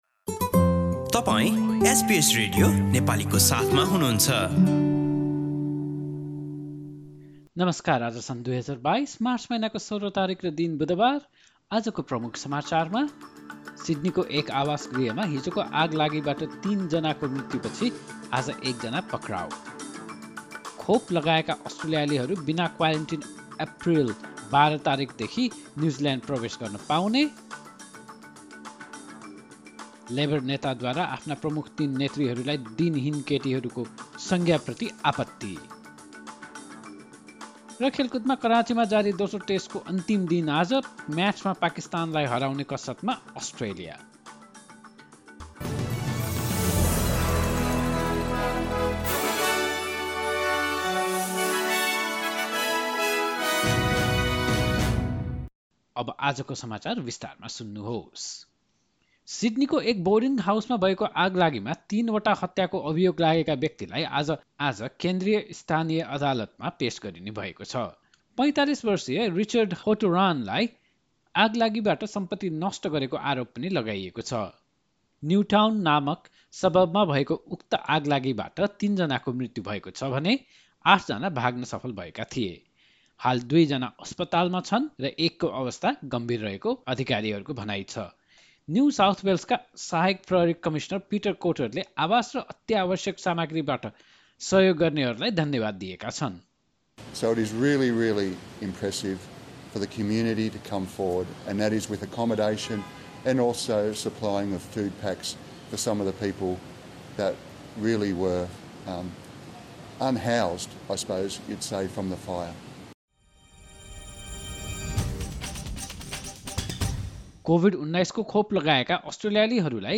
एसबीएस नेपाली अस्ट्रेलिया समाचार: बुधवार १६ मार्च २०२२